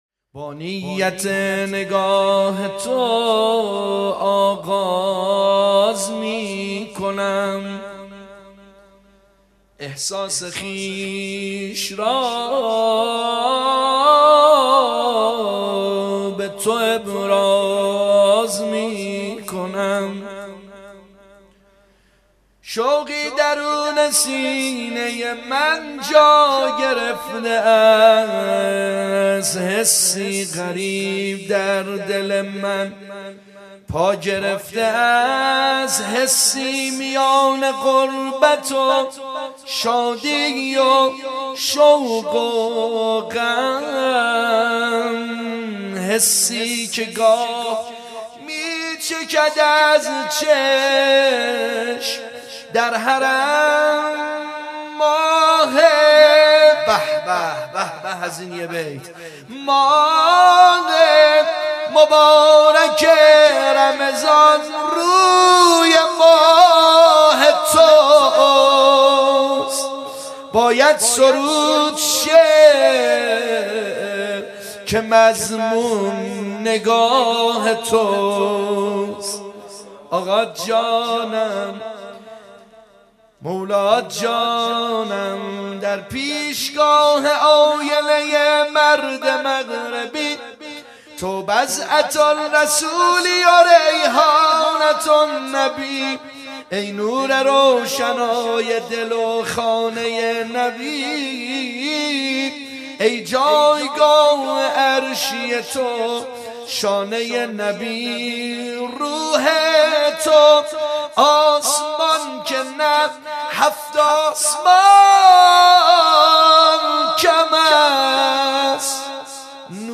شب شانزدهم ماه مبارک رمضان شام میلاد امام حسن مجتبی علیه السلام
? مدح: